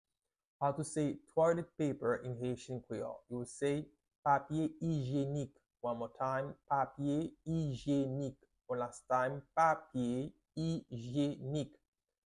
How to say "Toilet Paper" in Haitian Creole - "Papye Ijyenik" pronunciation by a native Haitian Teacher
“Papye ijyenik” Pronunciation in Haitian Creole by a native Haitian can be heard in the audio here or in the video below:
How-to-say-Toilet-Paper-in-Haitian-Creole-Papye-Ijyenik-pronunciation-by-a-native-Haitian-Teacher.mp3